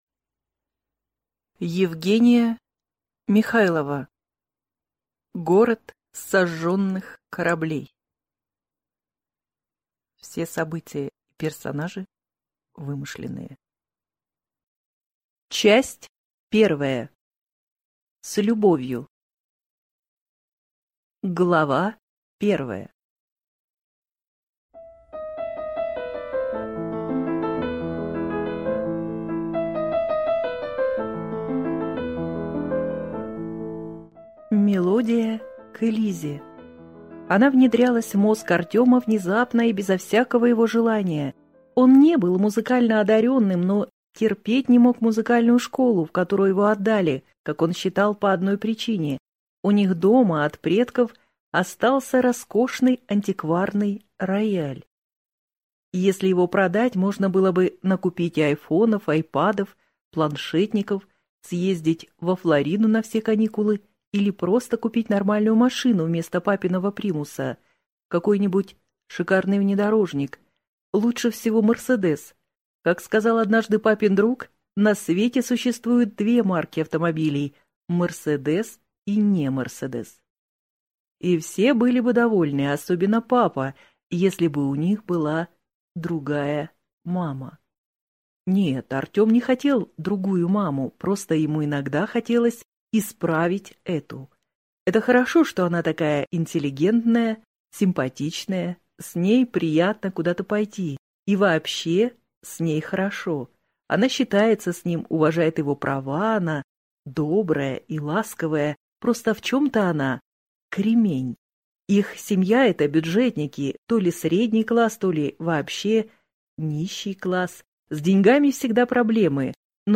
Аудиокнига Город сожженных кораблей | Библиотека аудиокниг